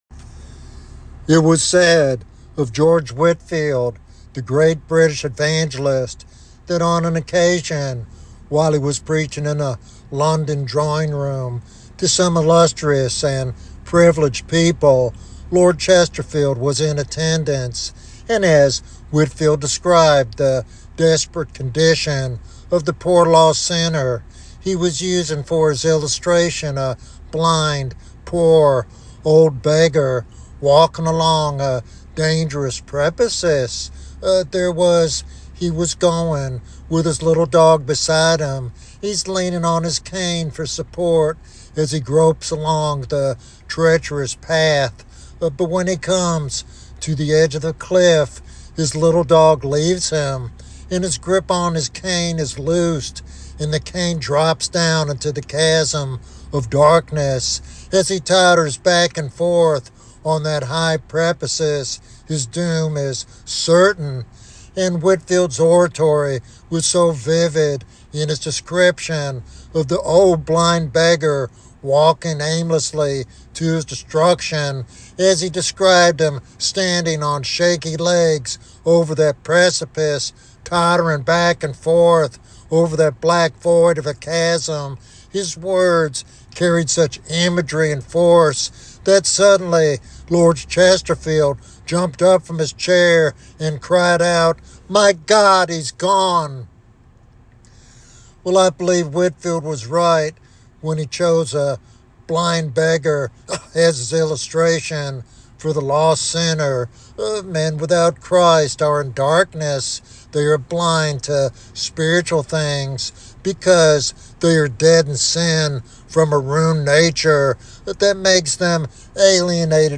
In this compelling prophetic sermon